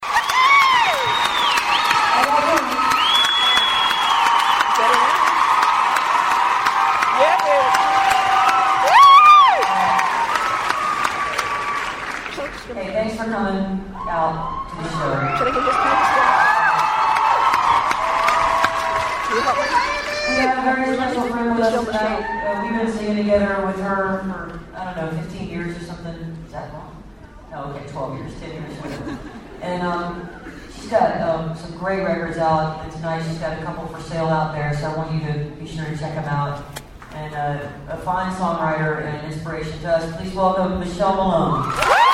lifeblood: bootlegs: 2000-02-17: jorgensen auditorium - storrs, connecticut